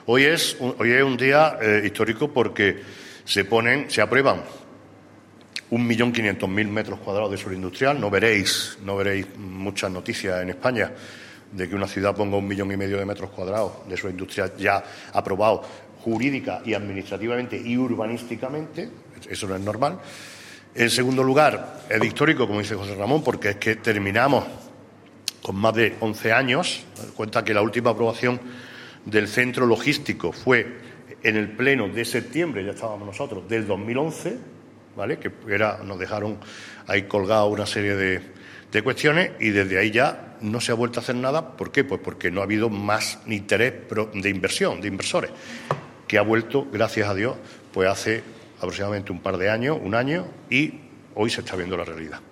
El alcalde de Antequera, Manolo Barón, acompañado por la teniente de alcalde de Urbanismo, Teresa Molina, y del concejal delegado de Obras e Inversiones, José Ramón Carmona, ha informado hoy en rueda de prensa de la aprobación (jurídica, administrativa y urbanísticamente) de un millón y medio de metros cuadrados de nuevo suelo industrial solucionando así tras más de una década el bloqueo del Centro Logístico de Antequera.
Cortes de voz